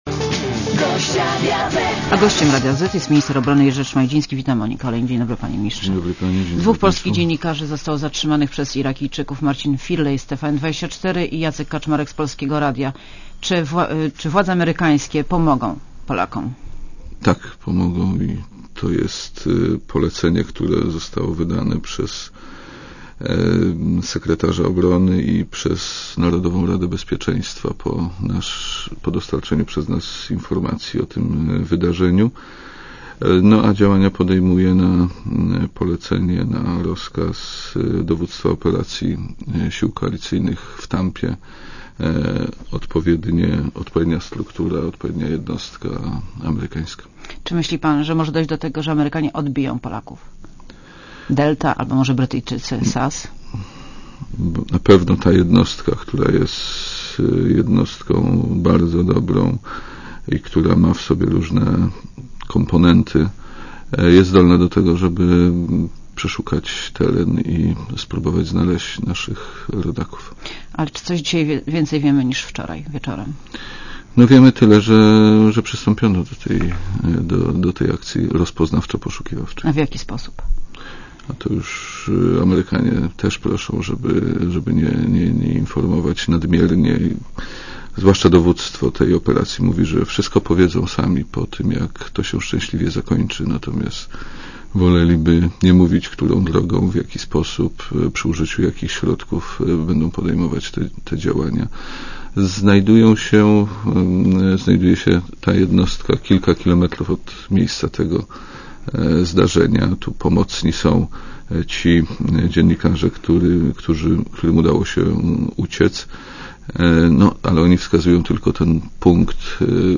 Monika Olejnik rozmawia z Jerzym Szmajdzińskim - ministrem obrony narodowej